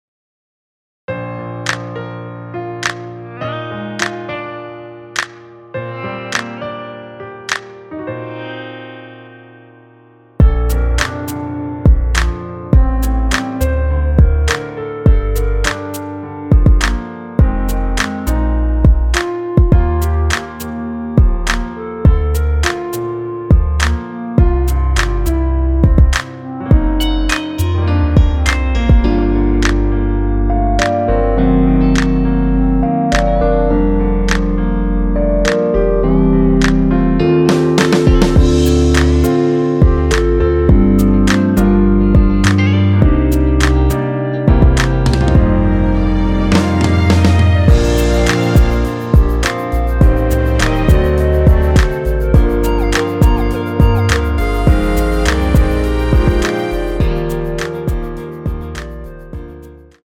원키에서(-2)내린 멜로디 포함된 MR입니다.(미리듣기 참조)
Bb
앞부분30초, 뒷부분30초씩 편집해서 올려 드리고 있습니다.